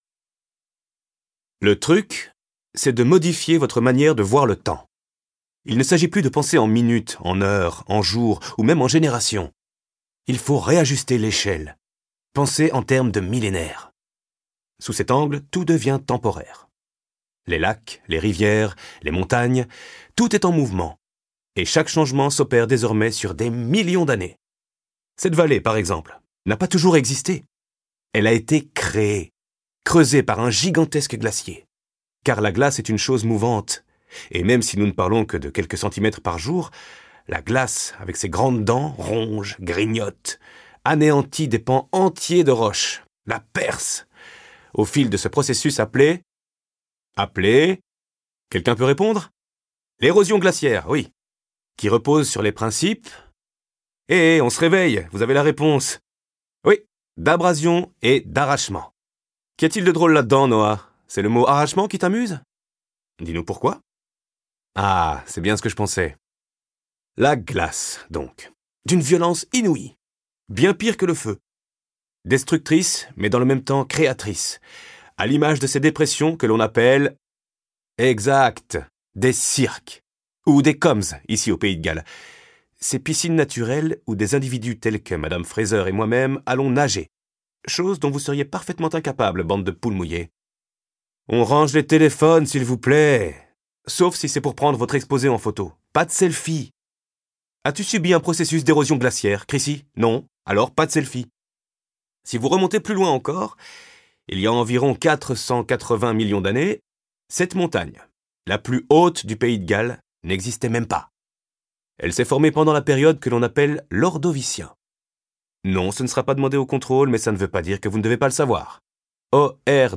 Une histoire d'amour portée par la lecture sensible